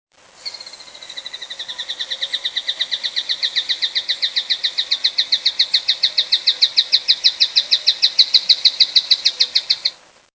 Scytalopus argentifrons (silvery-fronted tapaculo)
This is a Silvery-fronted Tapaculo (Scytalopus argentifrons) at Catarata La Paz in the montane cloud forest (Costa Rica), 11/16/98. These guys are often heard, but seldom seen, and the song sometimes continues uninterrupted for 2 minutes!